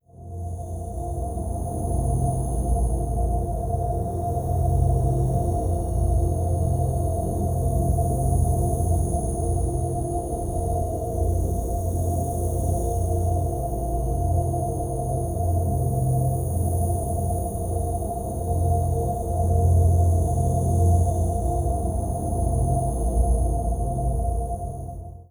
Efecto de reactor
reactor
Sonidos: Especiales